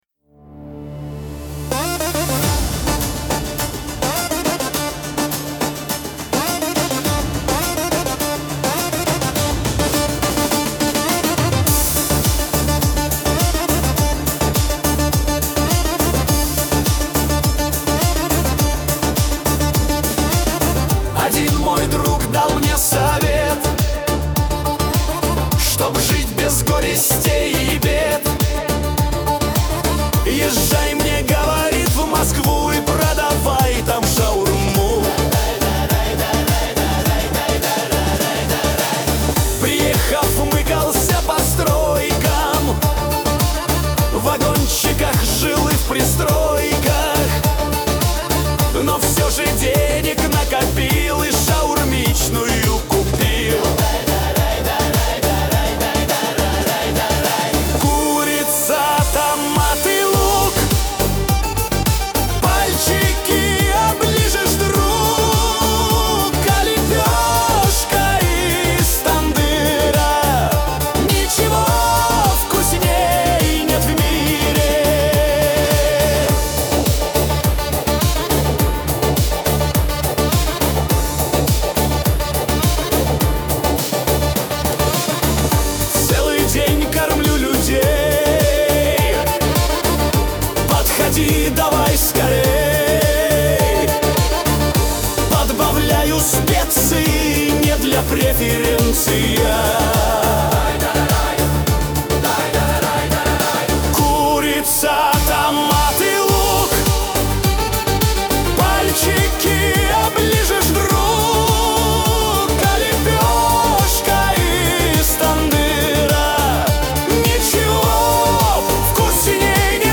mp3,7081k] Танцевальная